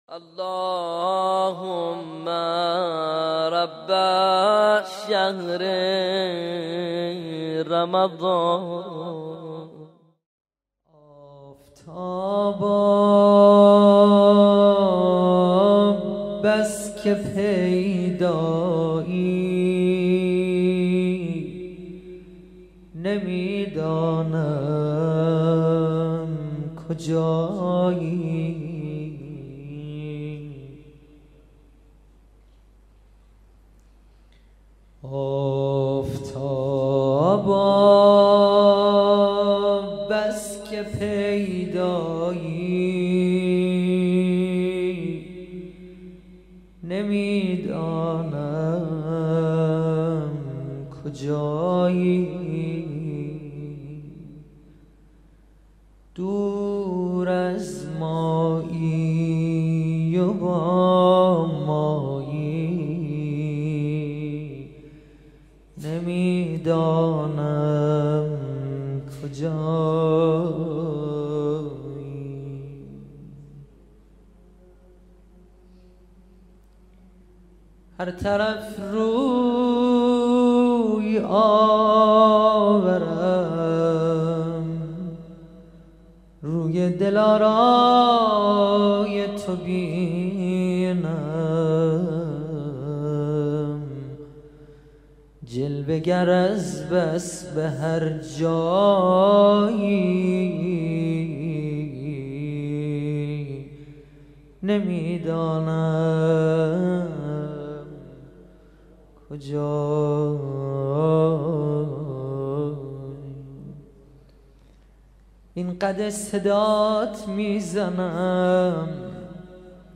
مناجات خوانی | آفتابا بس که پیدایی نمیدانم کجایی
نوحه و مناجات خوانی
در شب دوازدهم ماه رمضان 1437 هجری قمری